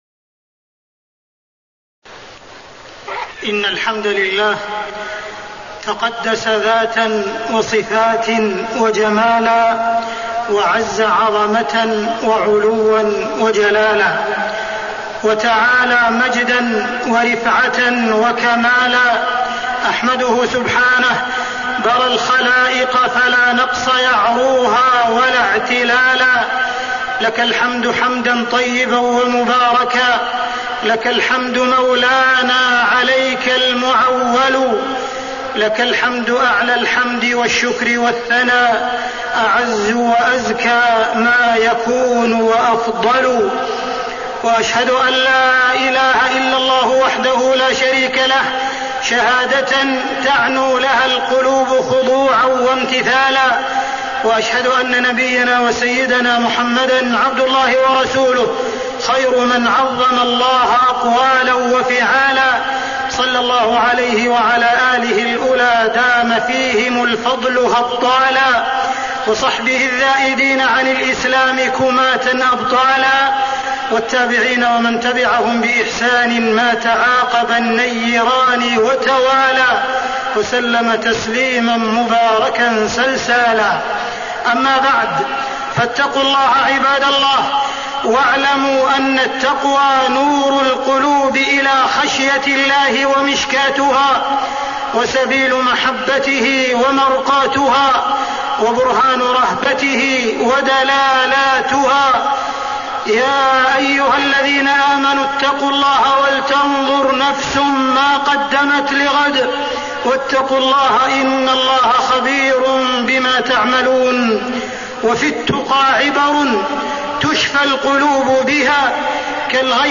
تاريخ النشر ٨ صفر ١٤٣٤ هـ المكان: المسجد الحرام الشيخ: معالي الشيخ أ.د. عبدالرحمن بن عبدالعزيز السديس معالي الشيخ أ.د. عبدالرحمن بن عبدالعزيز السديس عظمة الله وقدرته